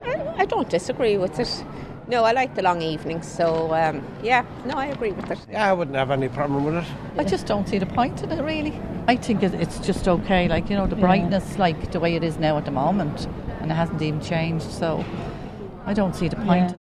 These people have mixed opinions: